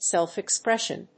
/ˌsɛlfɪˈksprɛʃʌn(米国英語), ˌselfɪˈkspreʃʌn(英国英語)/
アクセントsélf‐expréssion